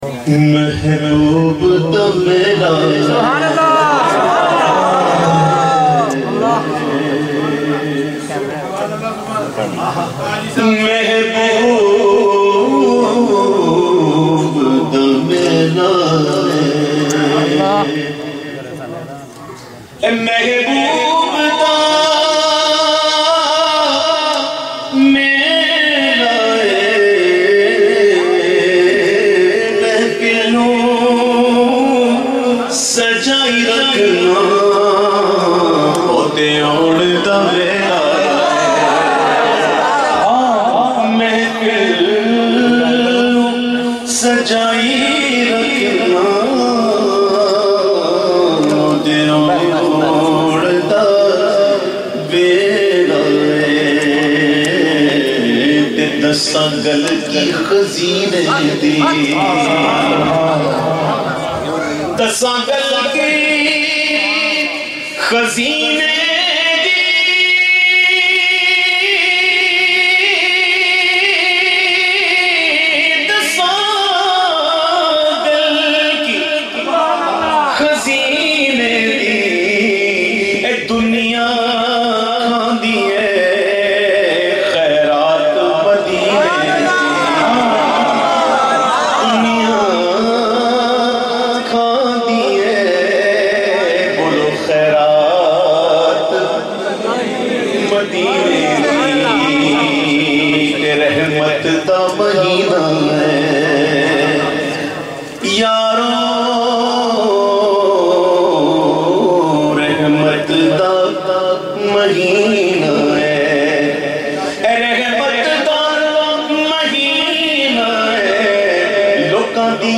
The Naat Sharif Mehboob Da Mela Aye recited by famous Naat Khawan of Pakistan owaise qadri.